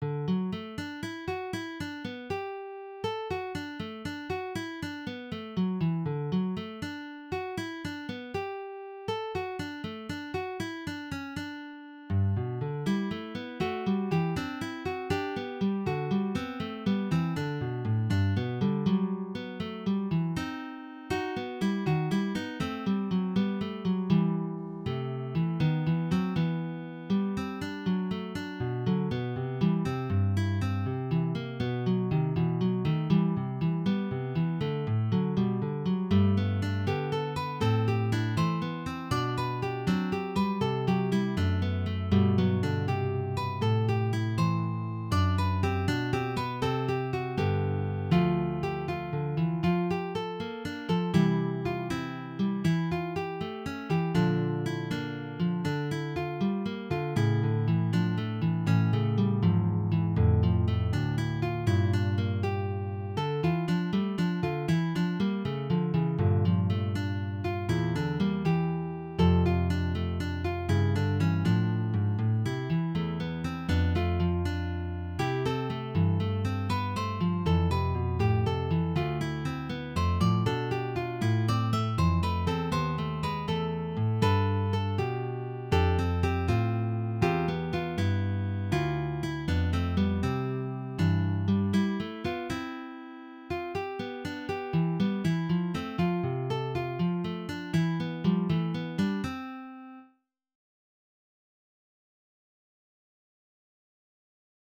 Progress on that fugue — first mid entry complete